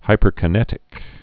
(hīpər-kə-nĕtĭk, -kī-)